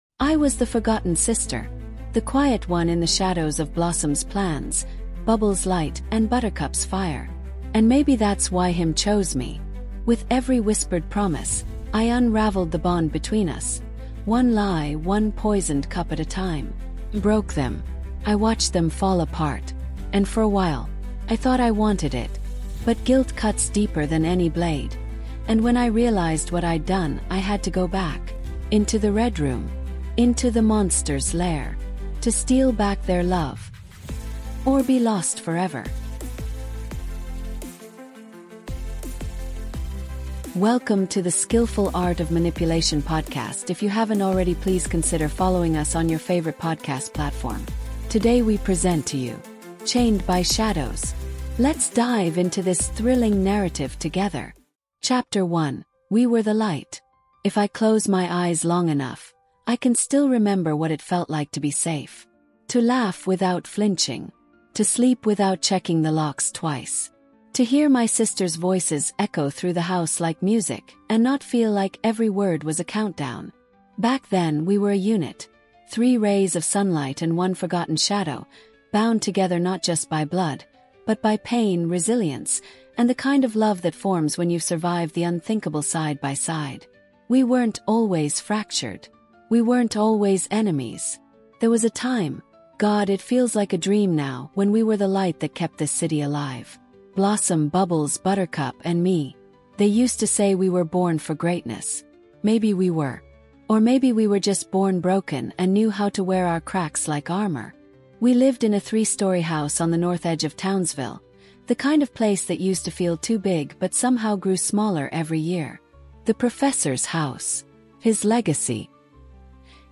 Chained by Shadows | Audiobook